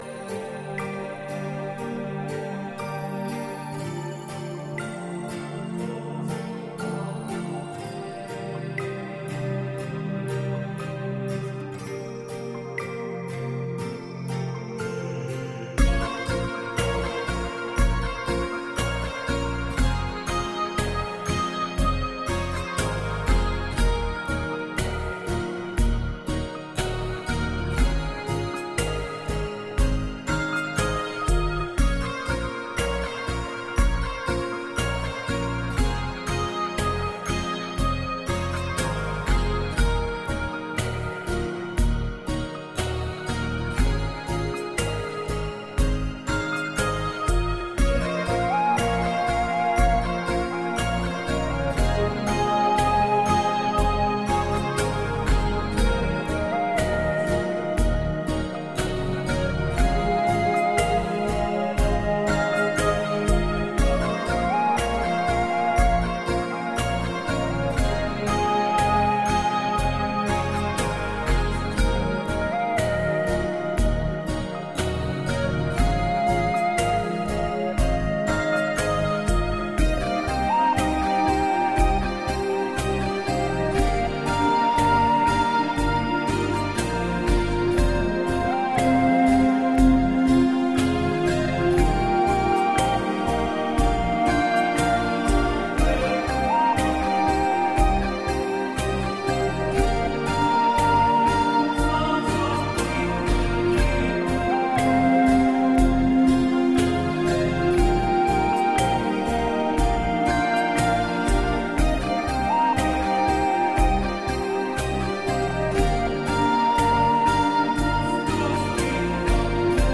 该唱片融入了诸多超凡脱俗的声音、并加入哥特式合唱团、吉他、鼓声等等形成了一个完整的多乐器音乐合集。